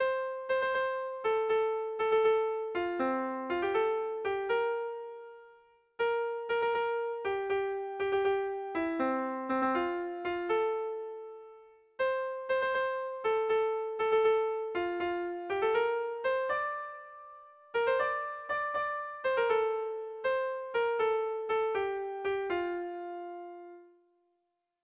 Kontakizunezkoa
A1BA2D